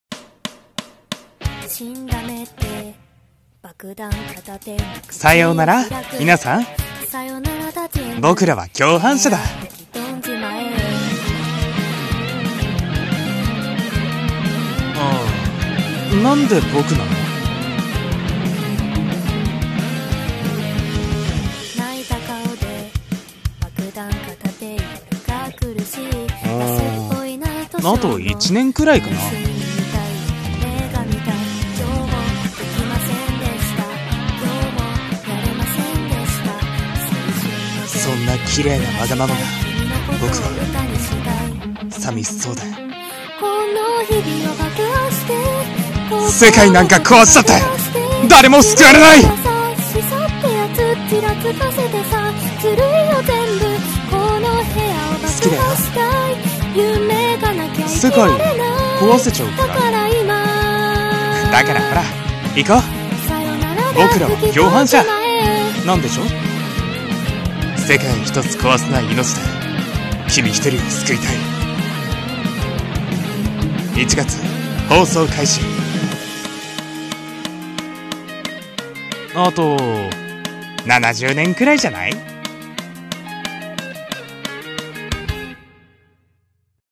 【アニメCM風声劇】イノセンシア ボマー